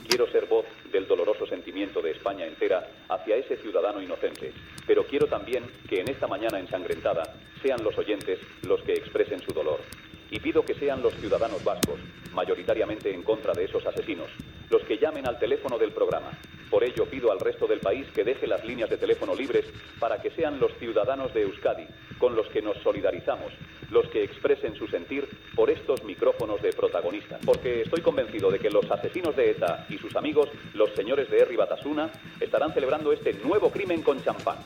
Enfrontament dialèctic entre Luis del Olmo i el portaveu d'Herri Batasuna Jon Idígoras, després de l'assassinat del capità de Farmàcia Alberto Martín Barrios, el dia anterior (Havia estat segrestat per ETA el 5 d'octubre)
Info-entreteniment